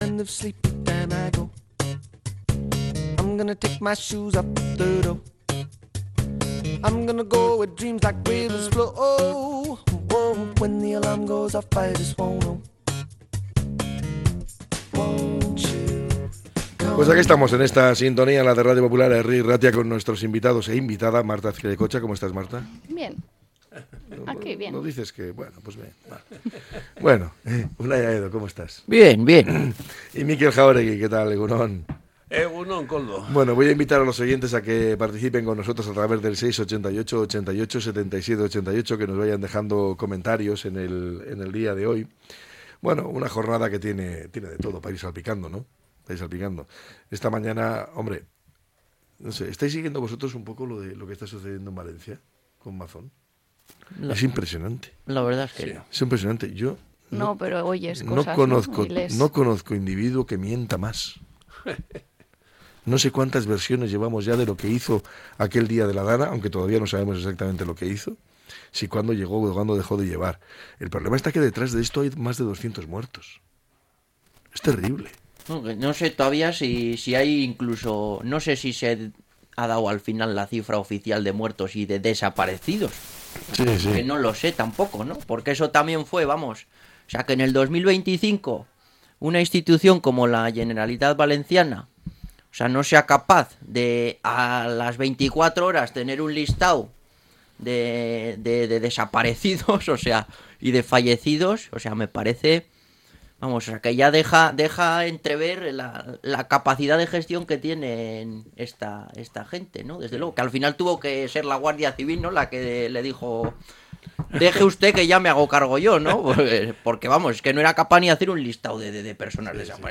La tertulia 26-02-25.